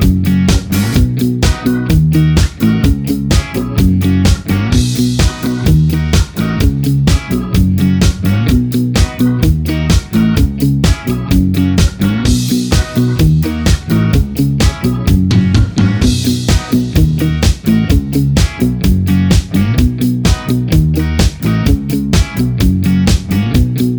Minus Main Guitar Pop (1970s) 3:35 Buy £1.50